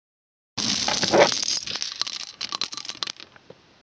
Download Chains sound effect for free.
Chains